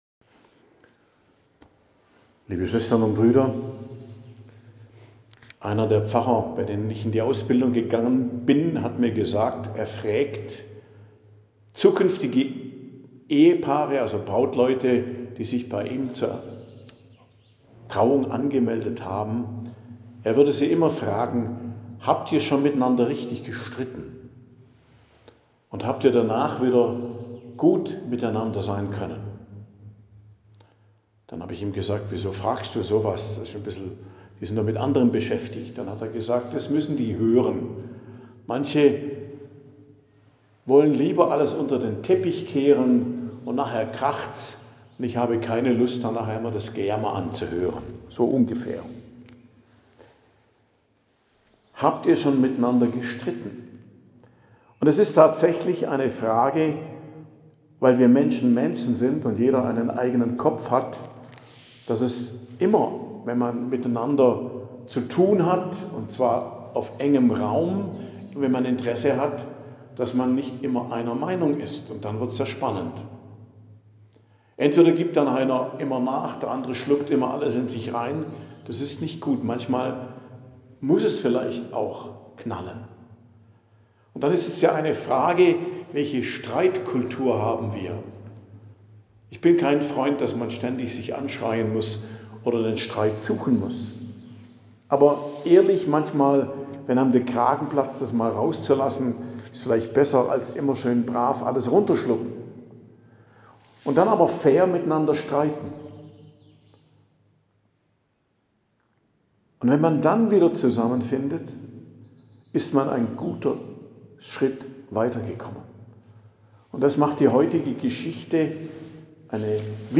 Predigt am Dienstag der 14. Woche i.J., 8.07.2025 ~ Geistliches Zentrum Kloster Heiligkreuztal Podcast